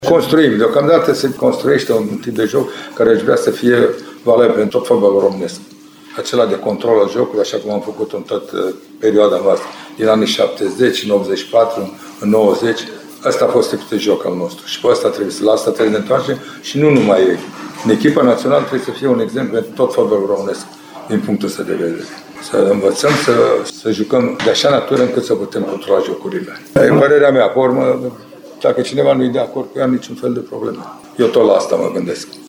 La final de joc, selecționerul Mircea Lucescu a vorbit despre „construcția unui tip de joc” și despre „posesie ca în 70, 84 și 90”: